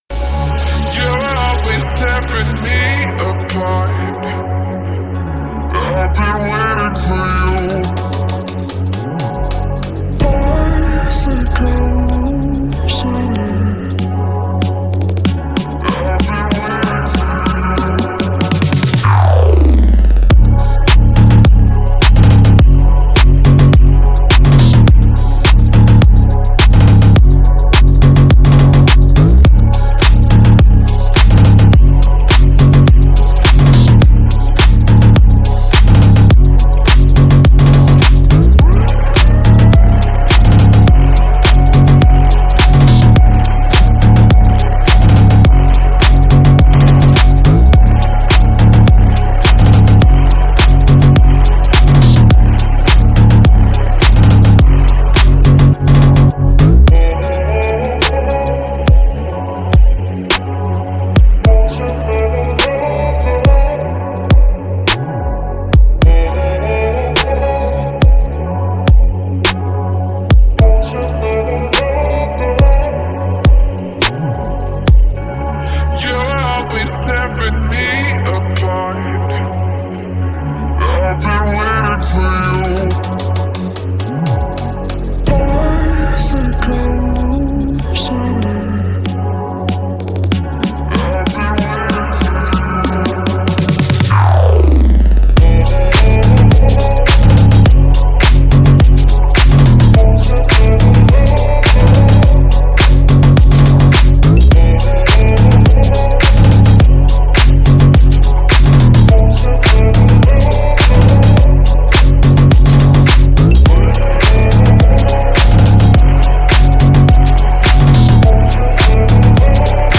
это зажигательная трек в жанре хип-хоп